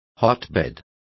Complete with pronunciation of the translation of hotbeds.